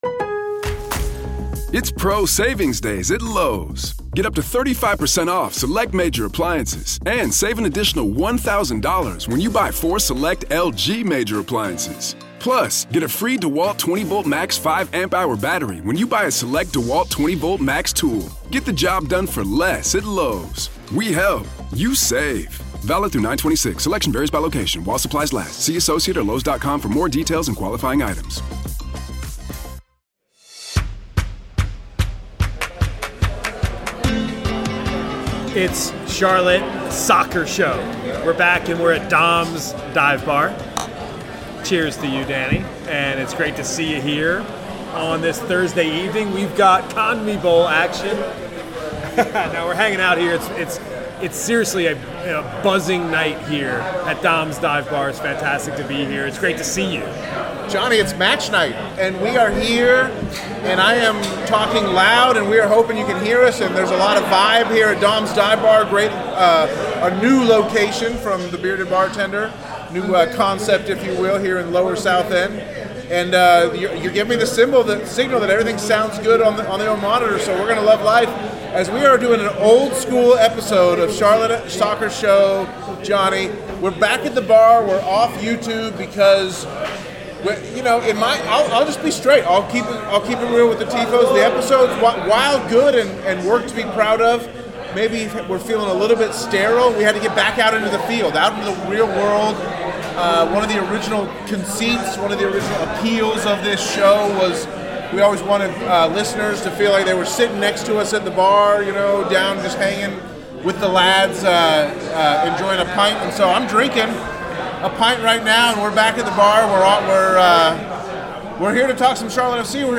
Plus, an uncomfortable conversation about The Captain Ashley Westwood. The duo bring their signature banter and sharp analysis to the mic.